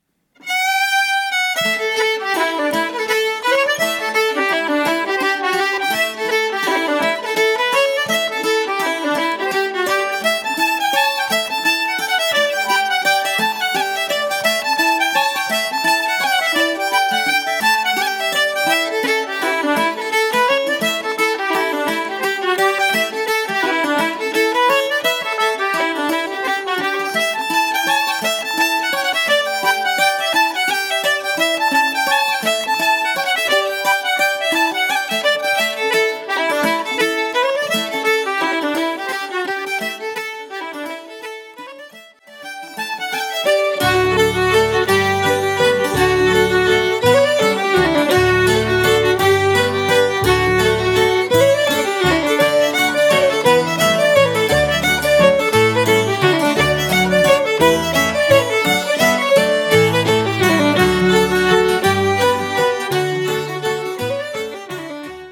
Fiddle and concertina from County Meath.